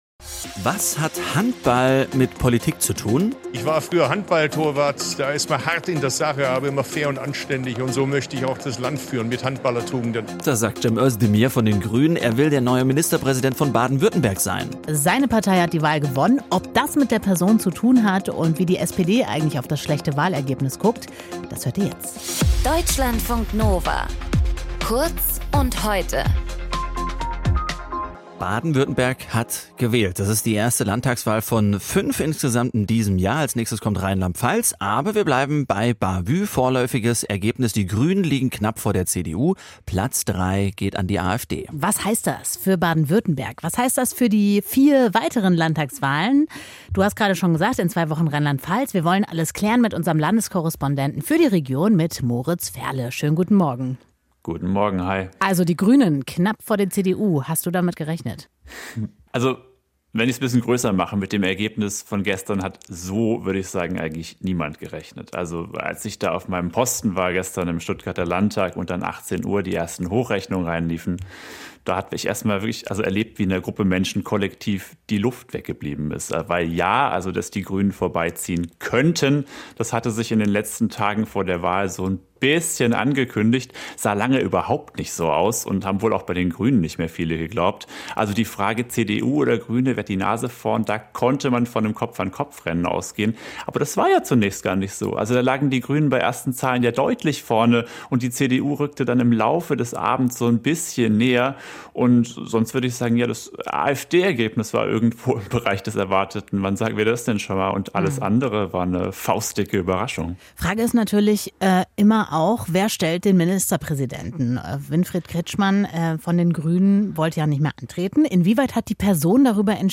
Moderator: